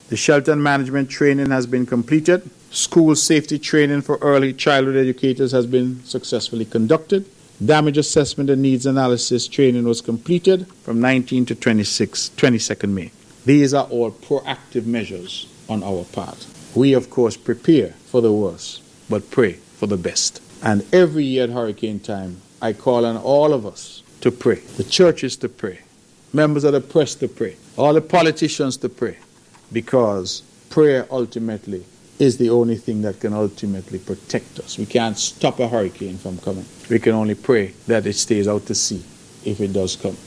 During his monthly press conference, Premier of Nevis Hon. Mark Brantley asked the nation to pray for safety of the country: